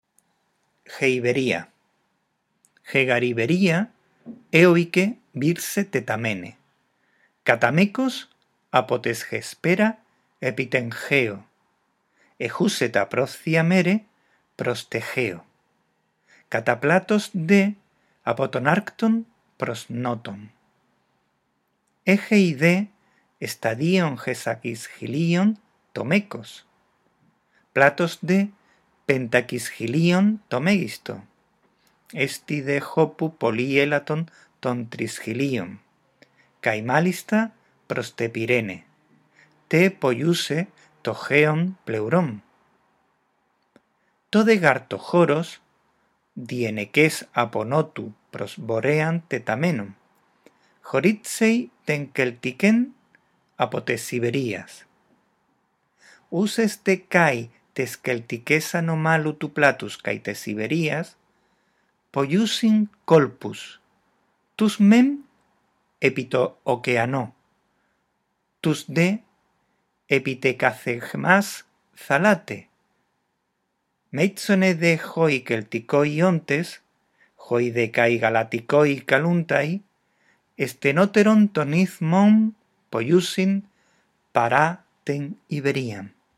Lee el texto en voz alta, respetando los signos de puntuación. Después escucha estos dos archivos de audio y repite la lectura.